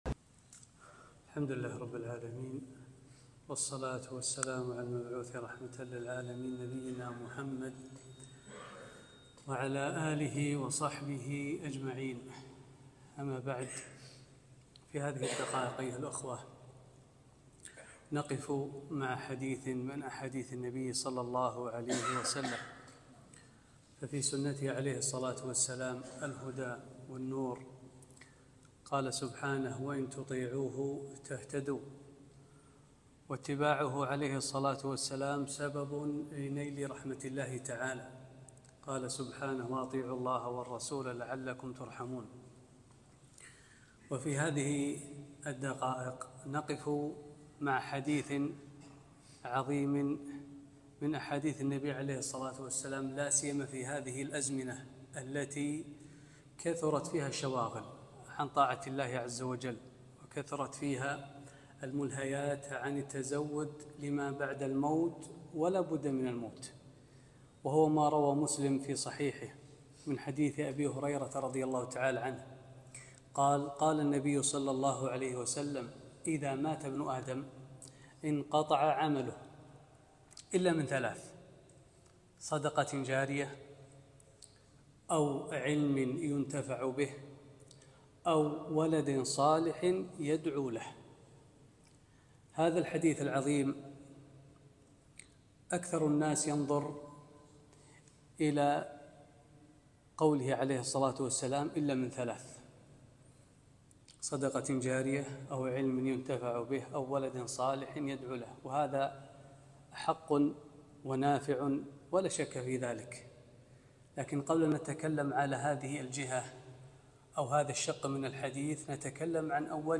كلمة - وقفة مع حديث